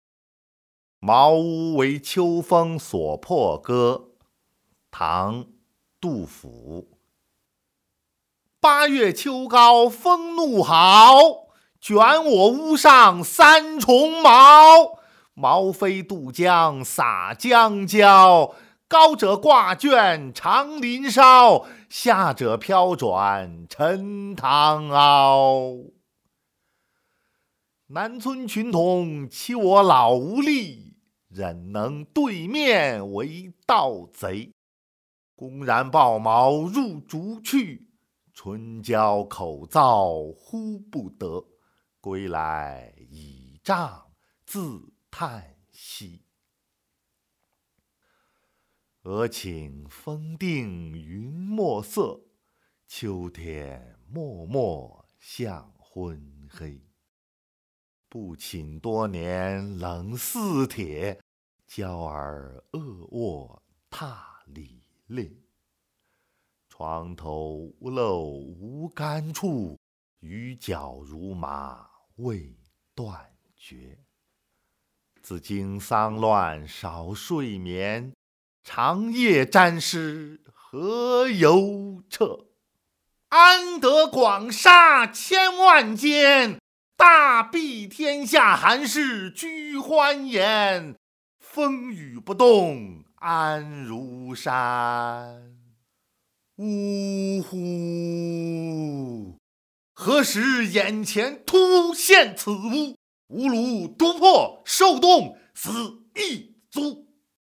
《茅屋为秋风所破歌》（读诵）［唐］杜　甫